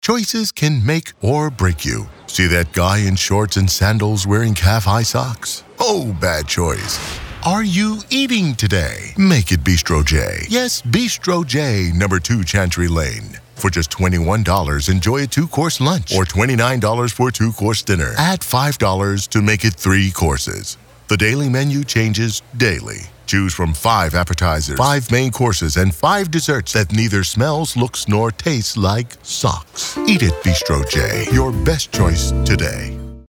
Male
American English (Native)
Assured, Authoritative, Bright, Bubbly, Confident, Cool, Corporate, Deep, Friendly, Reassuring, Sarcastic, Smooth, Streetwise, Warm, Versatile
Naturally deep, and deliciously rich voice that ads “flava” to your project.
Microphone: Sennheiser MKH 416, Nuemann TLM 103